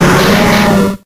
4d74cc7943c43b0eff5c3622eab4dcb32a477d63 infinitefusion-e18 / Audio / SE / Cries / KOFFING.ogg infinitefusion d3662c3f10 update to latest 6.0 release 2023-11-12 21:45:07 -05:00 12 KiB Raw History
KOFFING.ogg